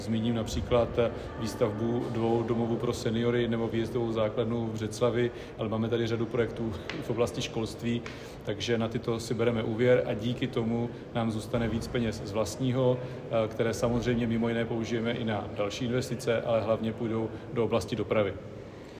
Hejtman Jan Grolich (KDU-ČSL) vysvětluje, jaké konkrétní projekty z něj plánují financovat.